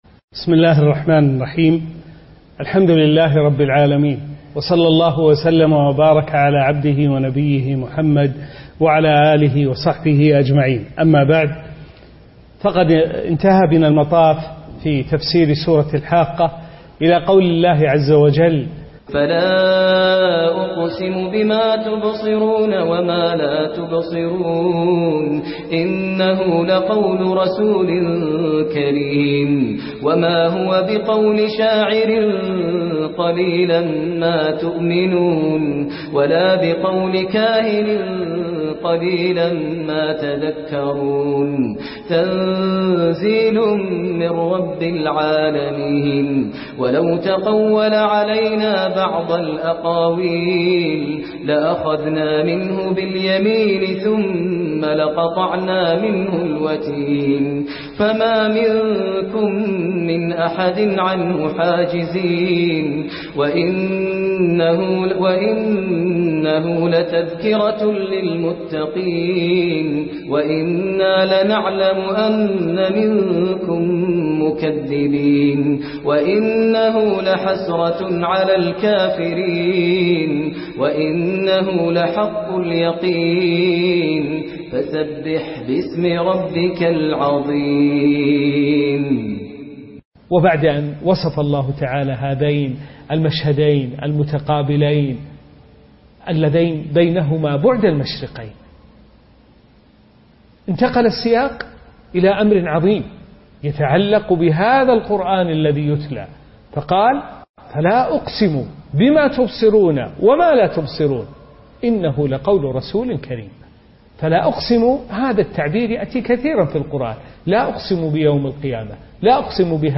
الدرس الخامس عشر : سورة الحاقة: من قوله تعالى: (فَلَا أُقْسِمُ بِمَا تُبْصِرُونَ) ، إلى آخر السورة.